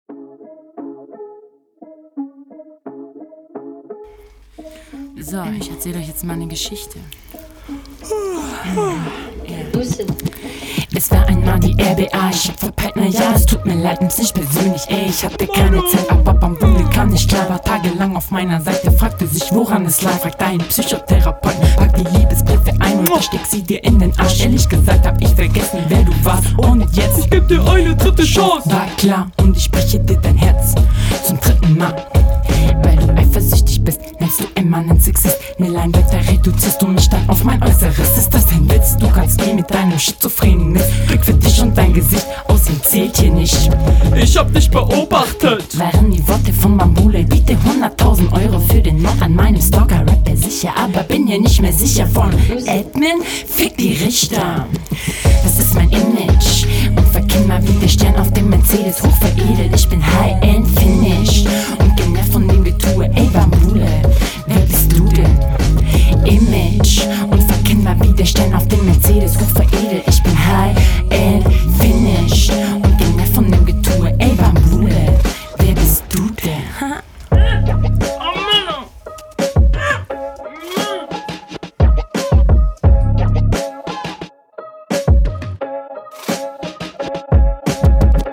Du schmatzt ein bisschen in deiner Aufnahme, ruhig …
Bitte trink n Schluck Wasser vorm Aufnehmen, die Mouthclicks sind ja extrem.